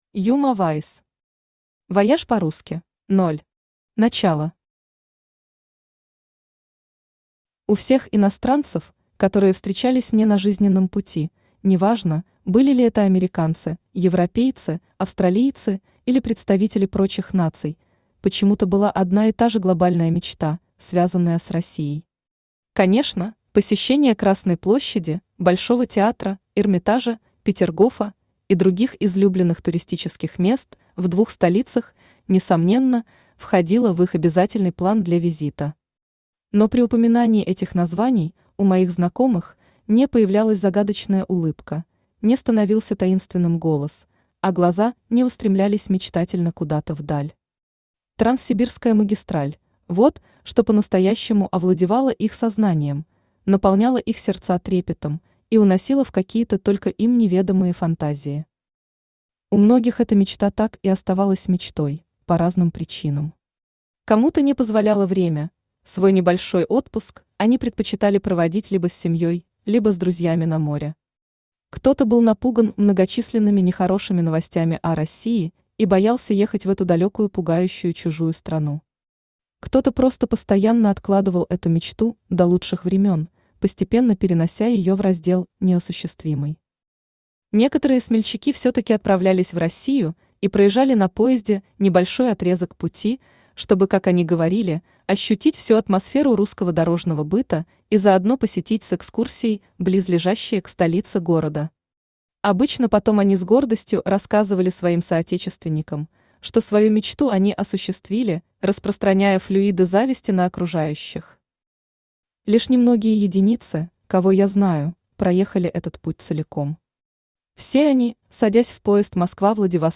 Aудиокнига Вояж по-русски Автор Uma Wice Читает аудиокнигу Авточтец ЛитРес.